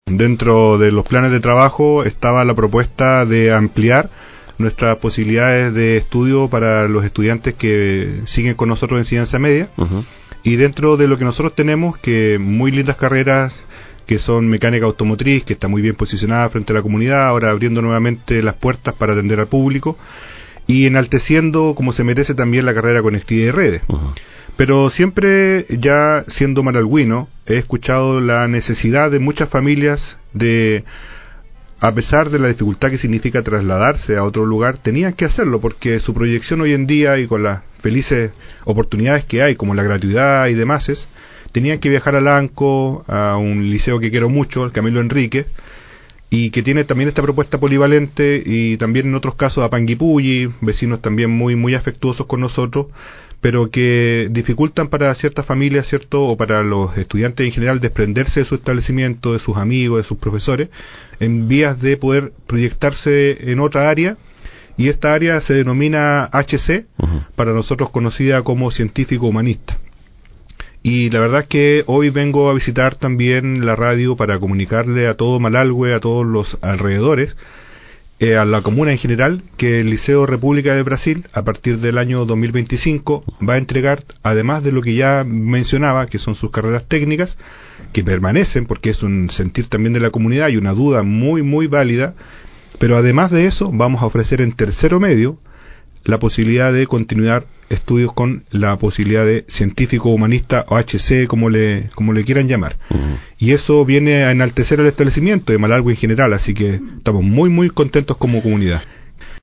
En entrevista con Portal Radio